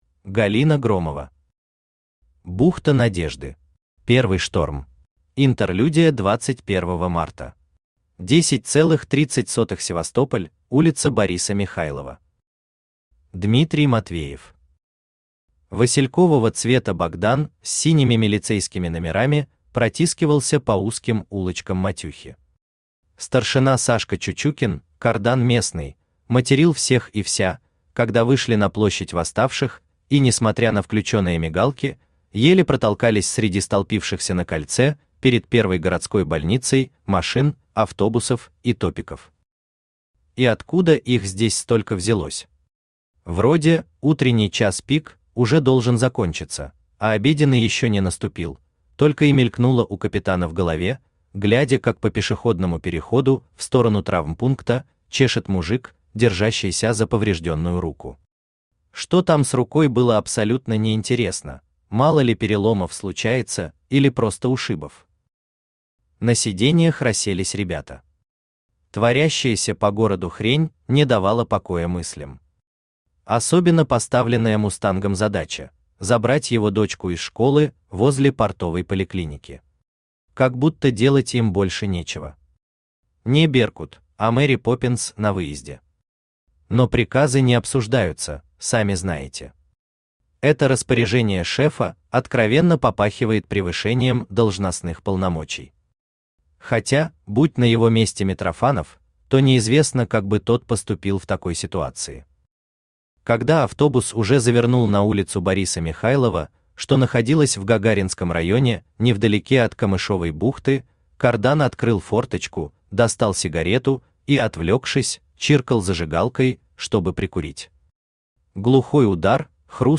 Аудиокнига Бухта Надежды. Первый шторм | Библиотека аудиокниг
Первый шторм Автор Галина Андреевна Громова Читает аудиокнигу Авточтец ЛитРес.